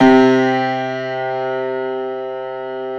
53d-pno06-C1.wav